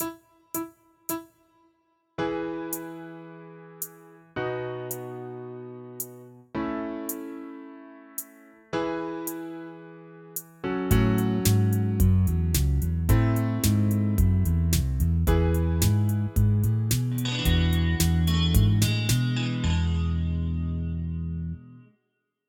nowhere_gtr.mp3